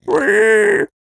Minecraft Version Minecraft Version 1.21.5 Latest Release | Latest Snapshot 1.21.5 / assets / minecraft / sounds / mob / piglin / retreat4.ogg Compare With Compare With Latest Release | Latest Snapshot